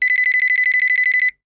ringtone.wav